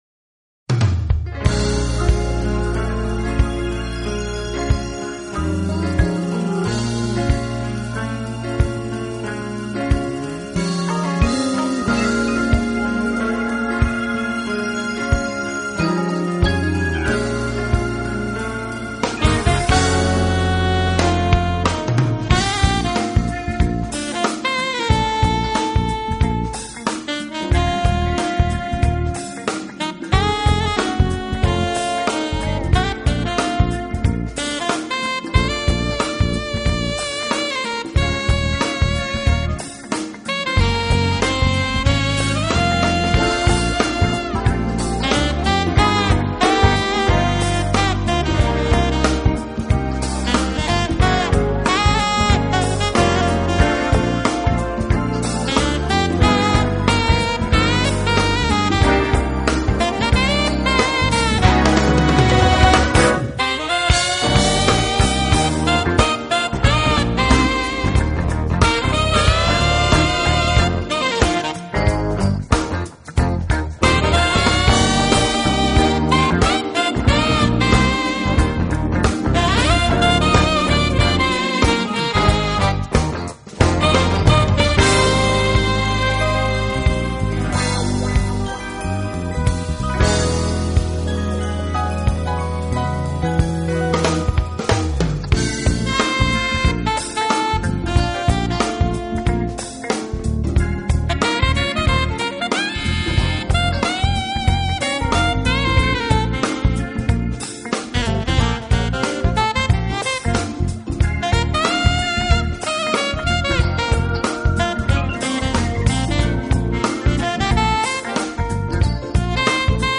Genero: Smooth Jazz
soprano, alto & tenor saxophone, keyboards
量的温柔而舒缓的曲子，非常适合夜晚来聆听。